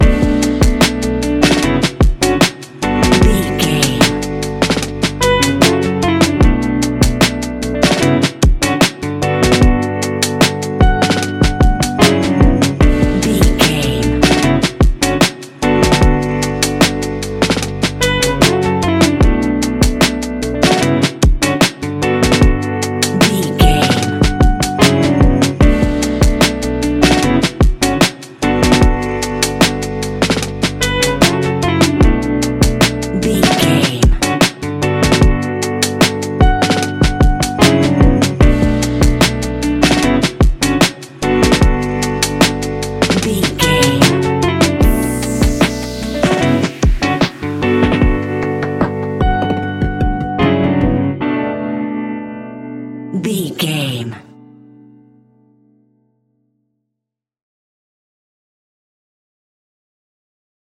Ionian/Major
B♭
laid back
Lounge
sparse
new age
chilled electronica
ambient
atmospheric
morphing